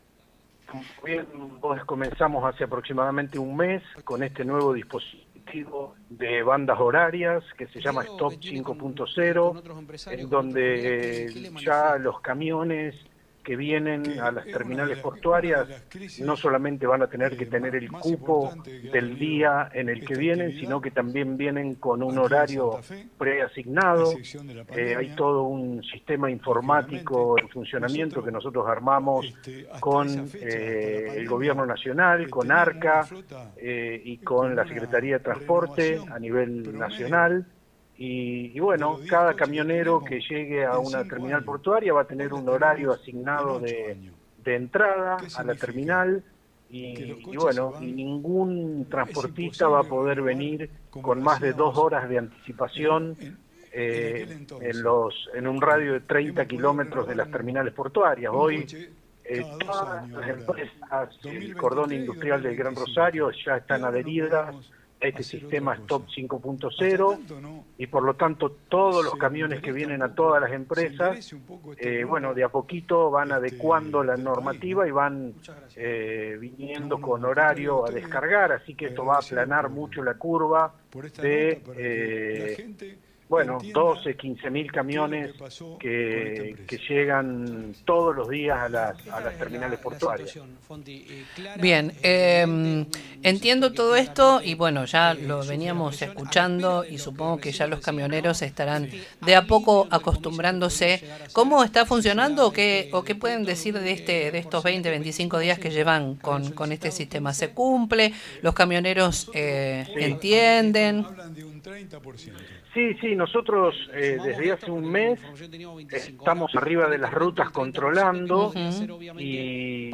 En diálogo con el programa Con Voz de FM 102.9 Nueva Estrella, el director de la Agencia Provincial de Seguridad Vial de Santa Fe, Carlos Torres, realizó un balance de los primeros días de implementación del operativo Stop 5.0, el sistema que establece turnos de descarga para transportistas con el objetivo de ordenar la circulación vehicular.